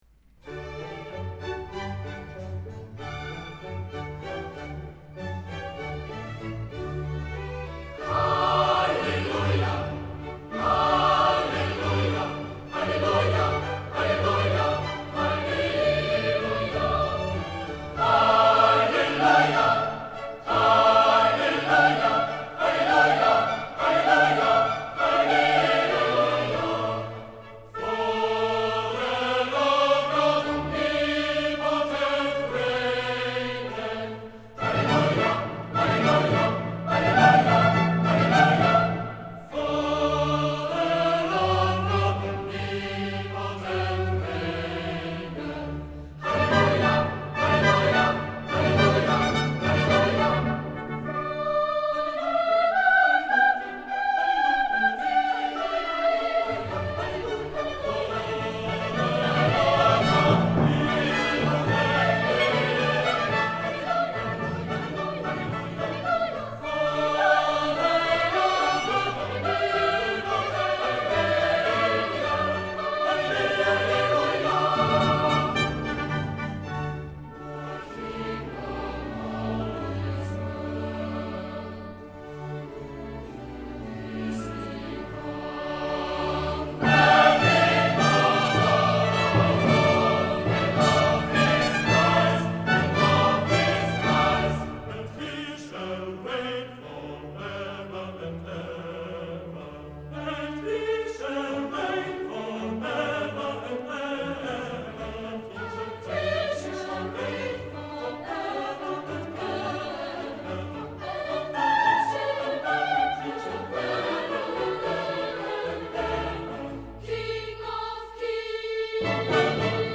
2-09-handel_-messiah-hwv-56-hallelujah-chorus.mp3